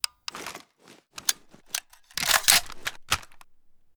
ru556_jam.ogg